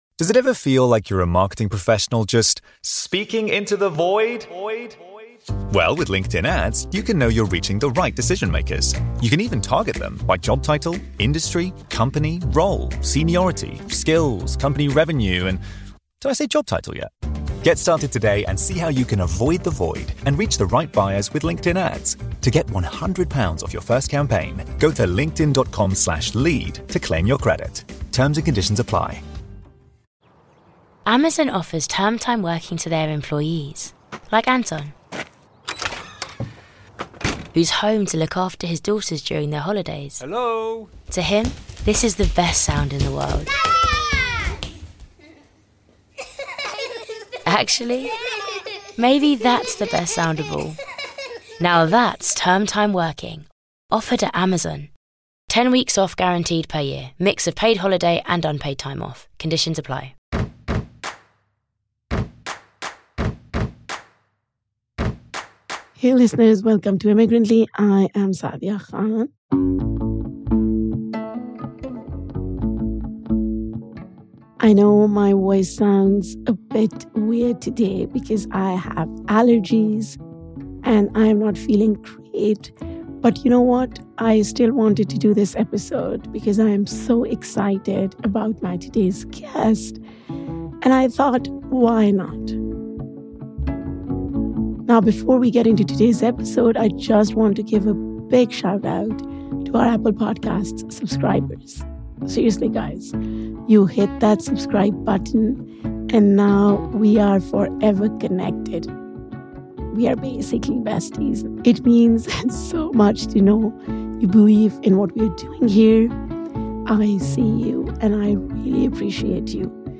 for a wide-ranging conversation on identity, diaspora, and the soundscapes of belonging.